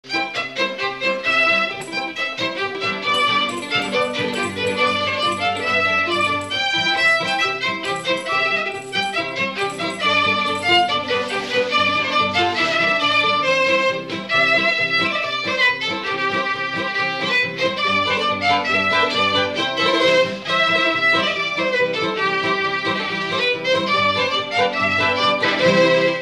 Mémoires et Patrimoines vivants - RaddO est une base de données d'archives iconographiques et sonores.
Polka
Instrumental
danse : polka